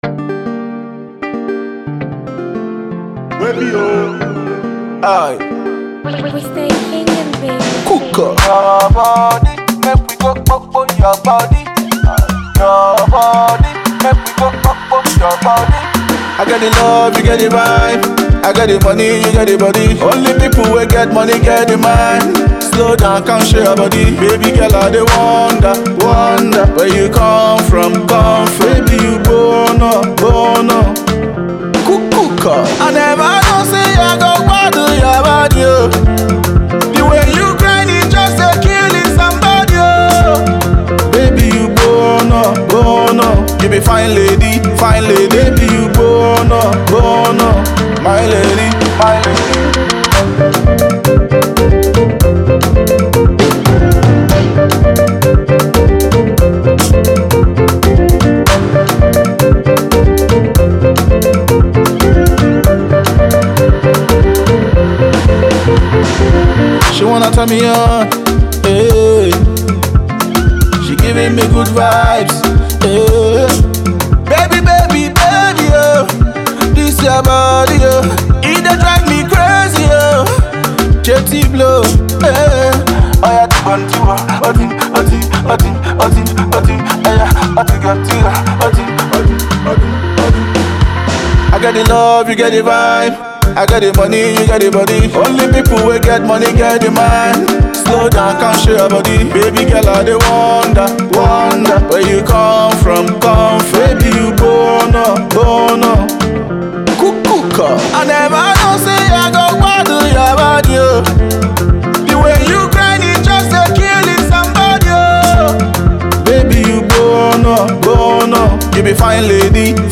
Afro-pop
very rhythmic dance tune